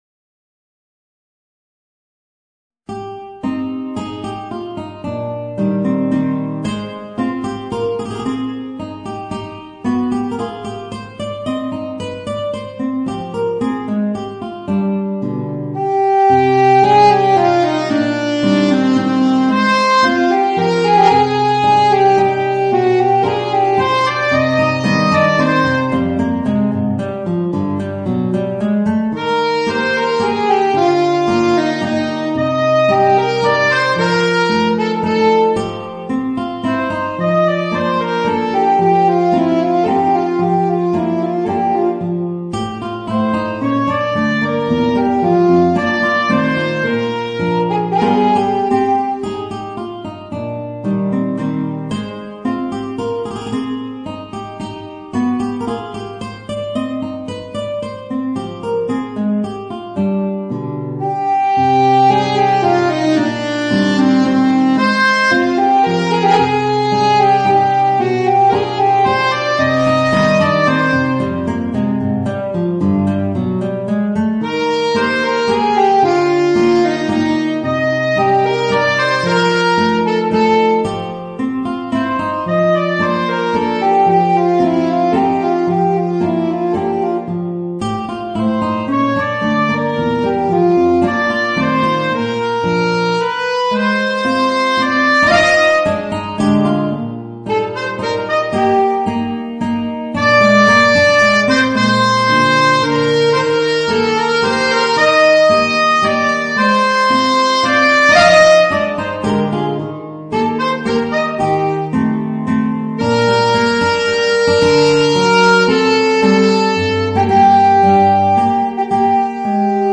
Voicing: Guitar and Alto Saxophone